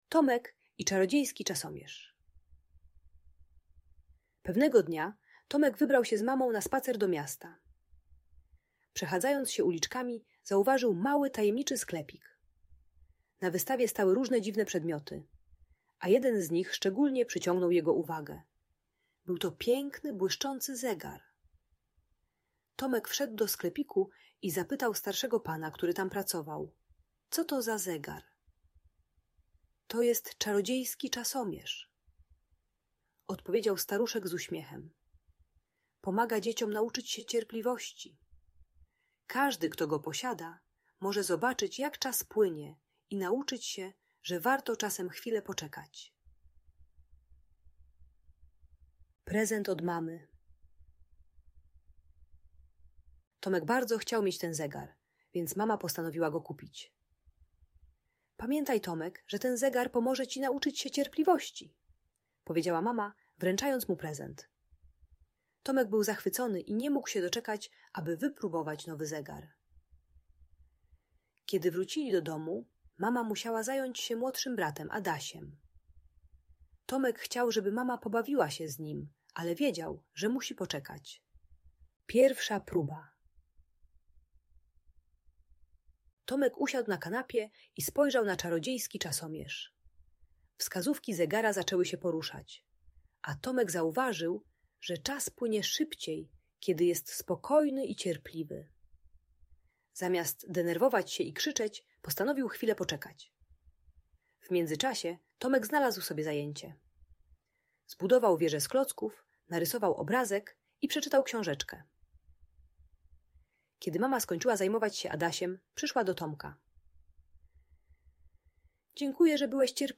O Tomku i Czarodziejskim Czasomierzu - Ucz się cierpliwości - Audiobajka